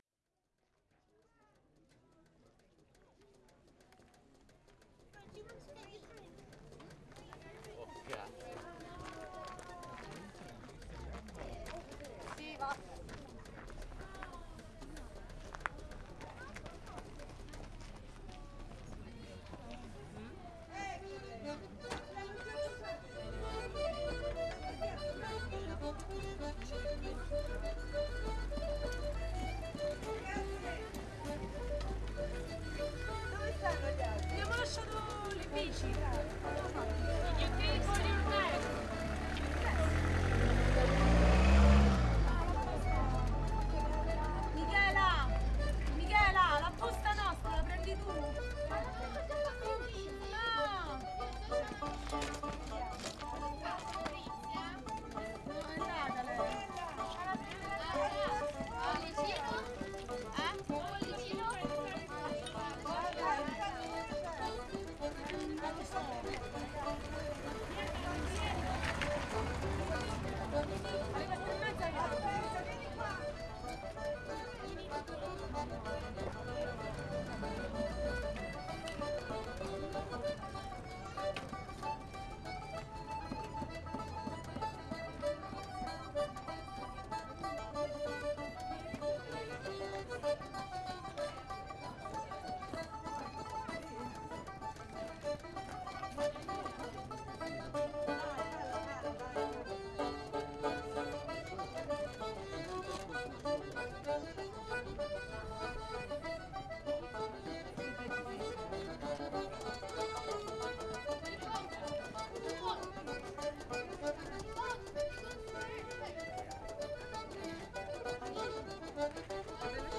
Aran_music_ambiX.wav